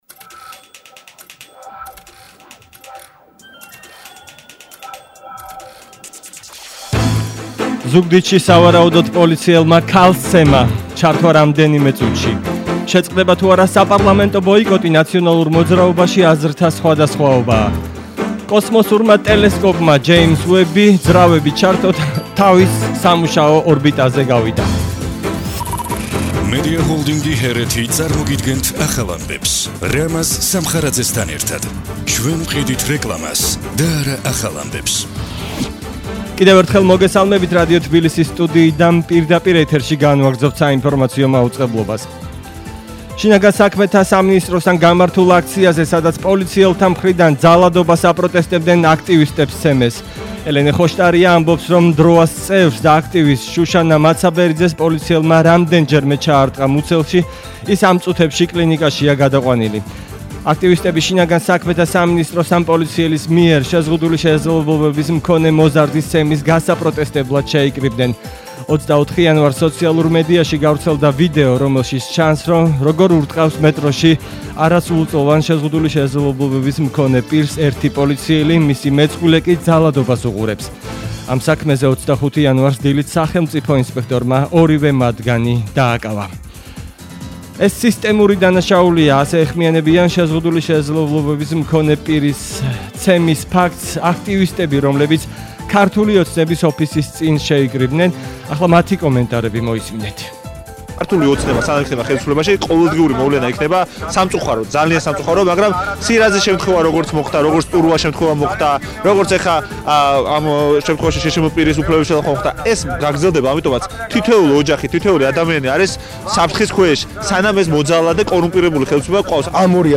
ახალი ამბები 19:00 საათზე – 25/01/22